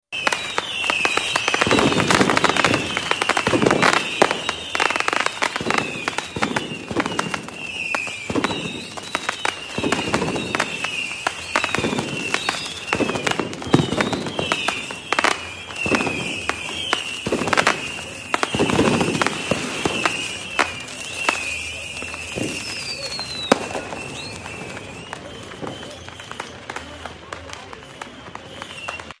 Fireworks - Салют, фейерверк
Отличного качества, без посторонних шумов.